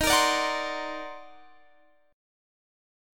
Listen to EbmM13 strummed